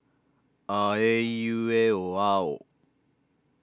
地声の機能が働いている喋り声
音量注意！